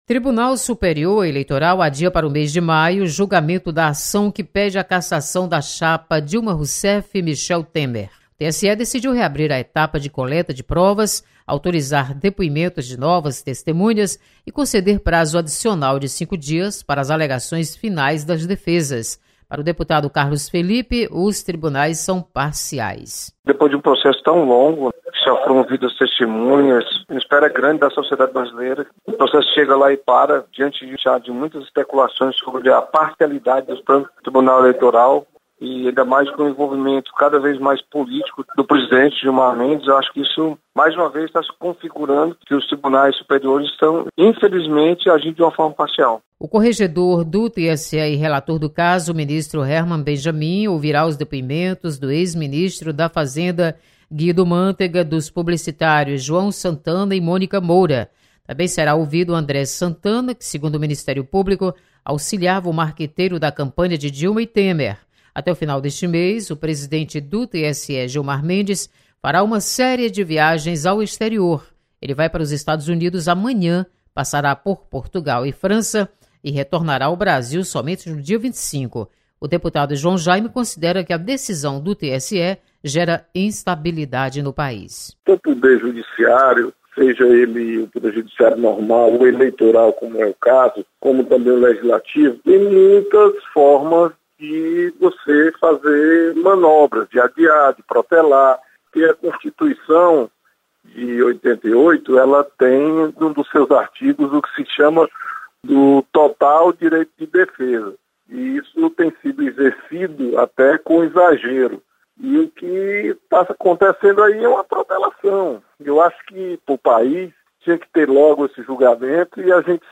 Adiado julgamento da chapa Dilma/Temer no TSE. Repórter